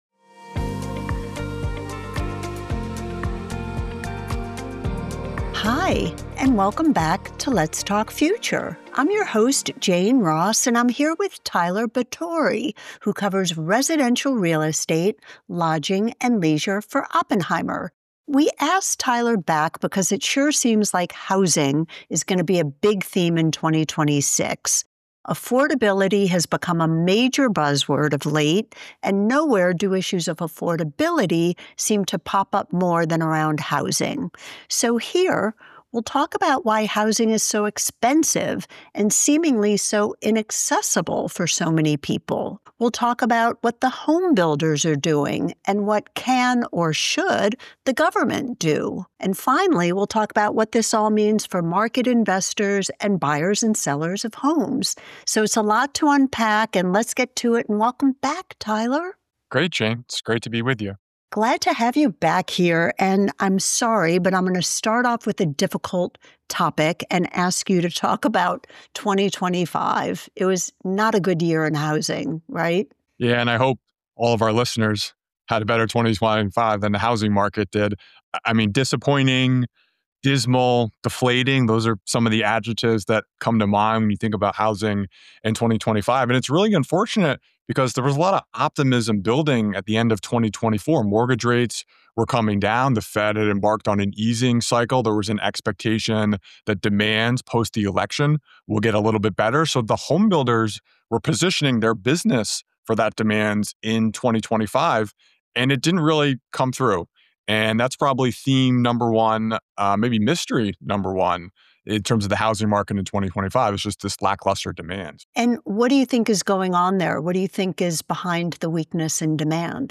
A Podcast Conversation